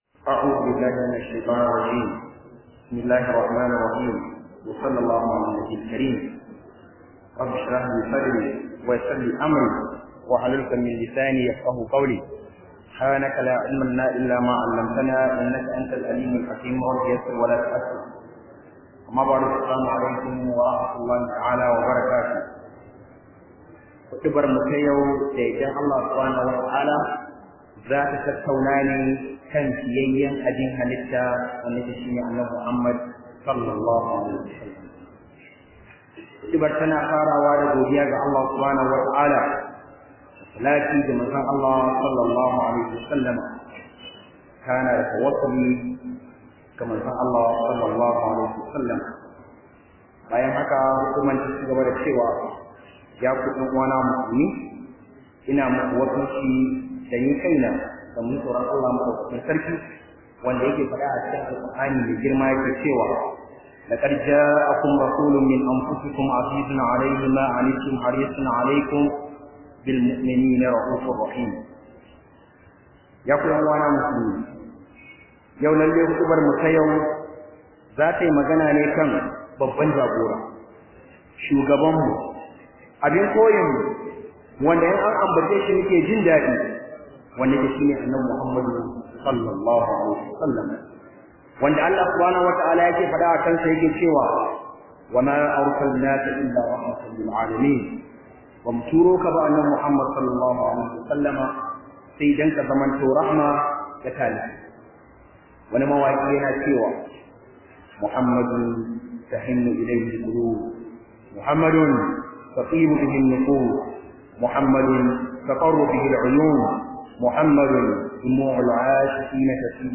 018 khudubah kan fiyayyan Halittar .mp3